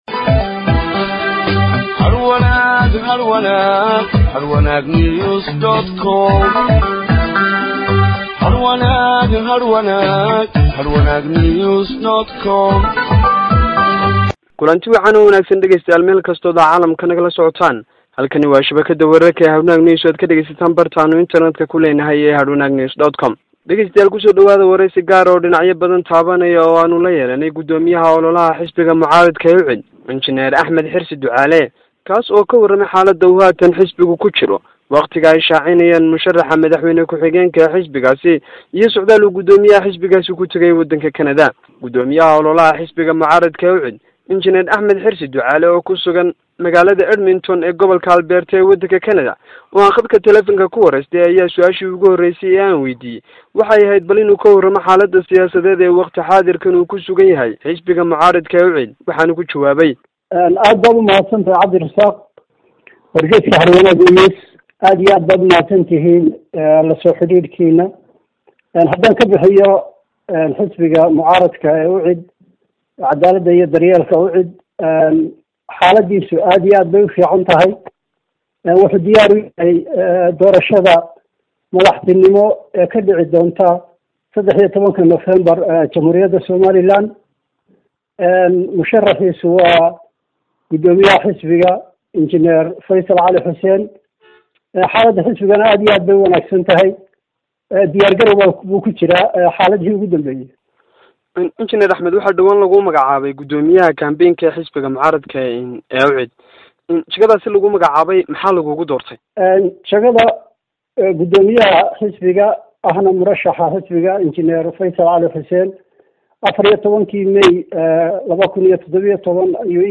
Waraysi